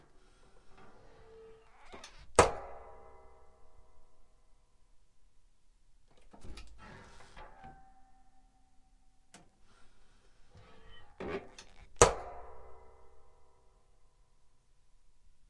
热压机关闭和打开
描述：一台T恤热压机的关闭和打开。
Tag: 热压 打开 关闭 吱吱 踩住 金属 铮铮 金属 heatpress